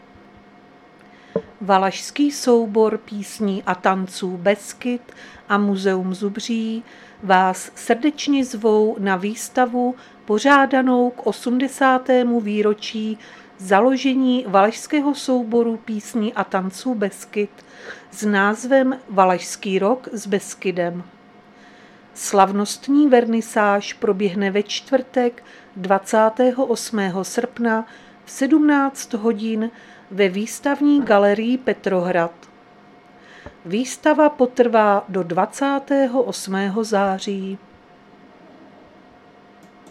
Záznam hlášení místního rozhlasu 28.8.2025
Zařazení: Rozhlas